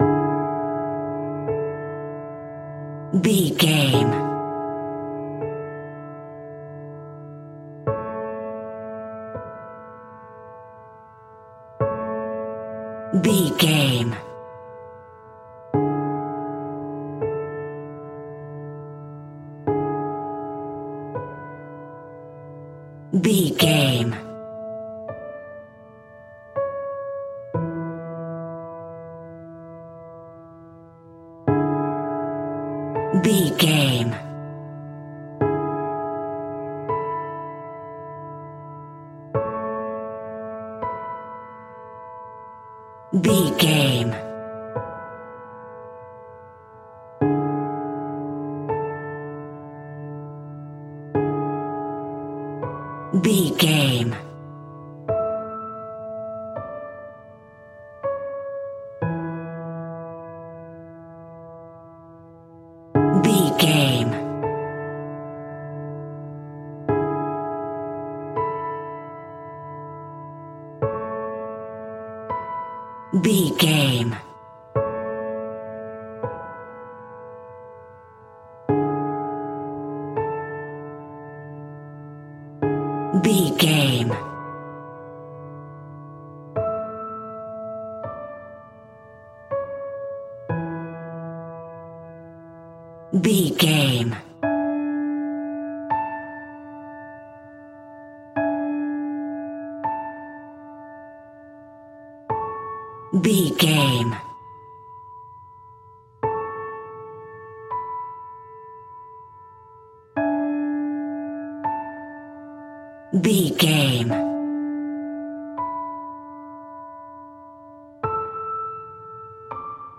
Mournful and sad piano music in a minor key.
Regal and romantic, a classy piece of classical music.
Ionian/Major